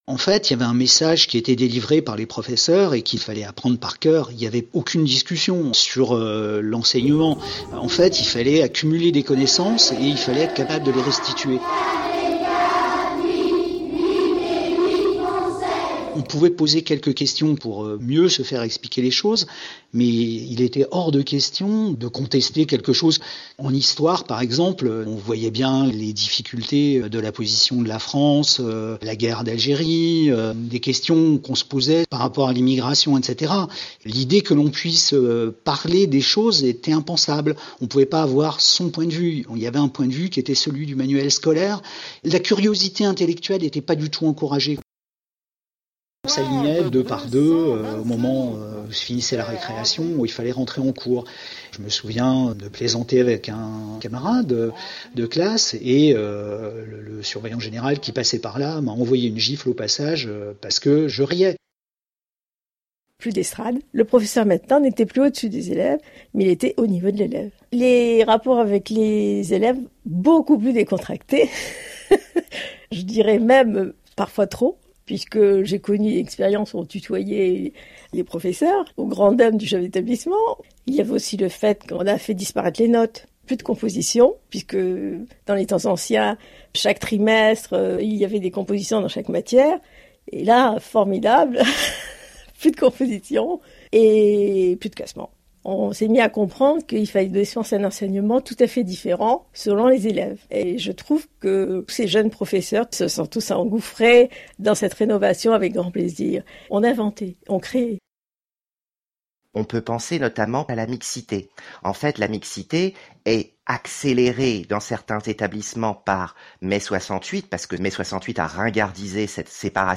A la radio, il y a quelques jours, il y avait ces témoignages de gens qui étaient un peu plus âgés alors. Sur l’école, l’enseignement.